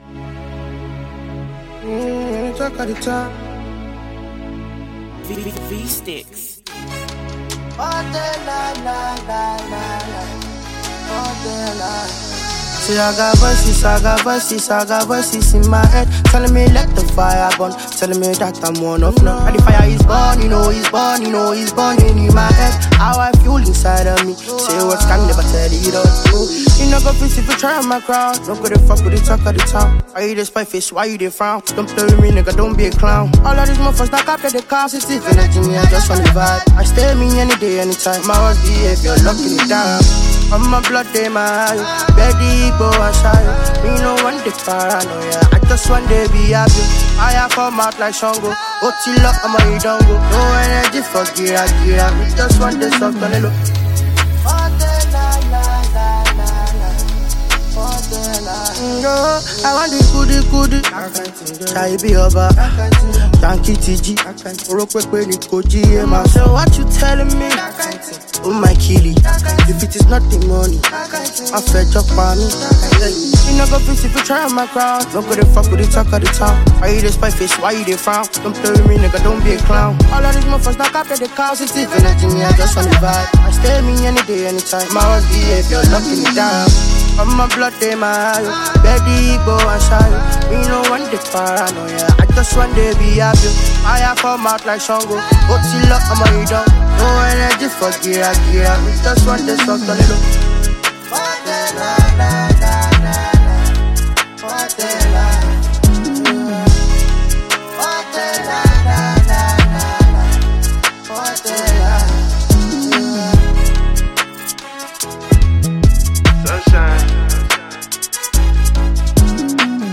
Afrobeats
Afrobeat with contemporary influences